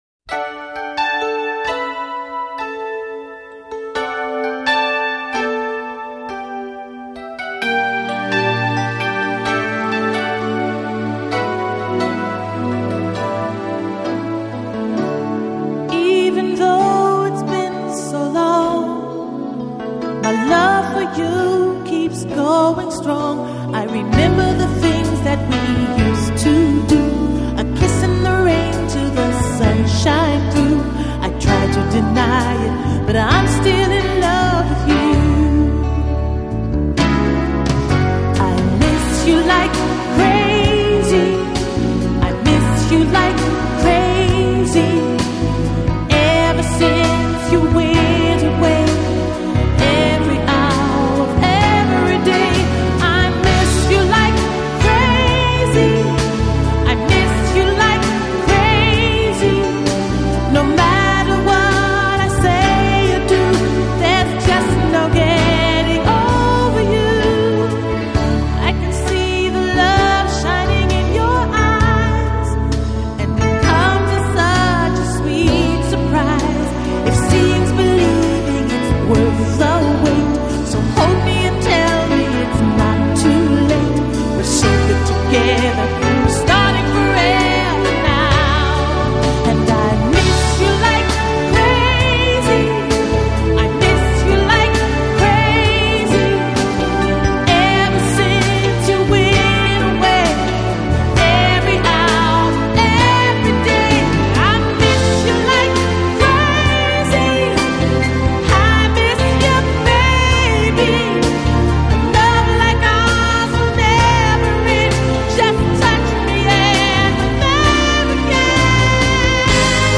Жанр: Jazz, Funk, Soul, Pop, Easy Listening, Disco